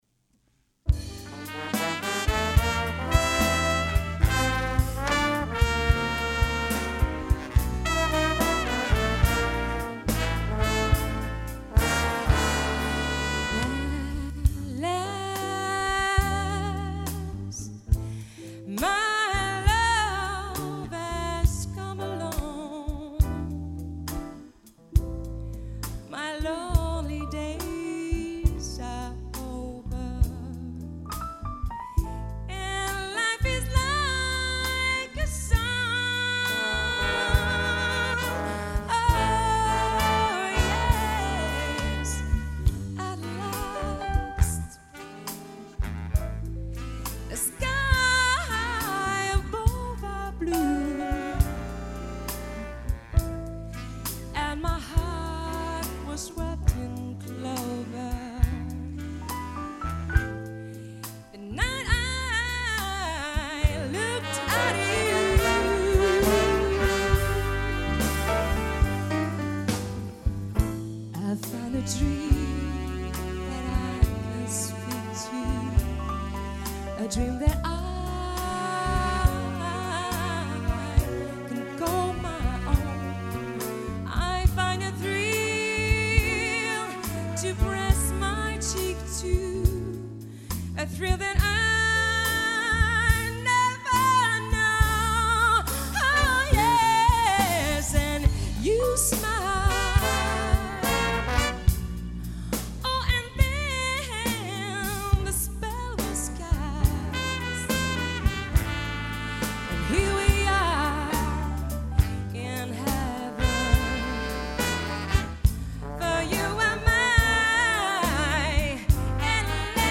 Chanteuse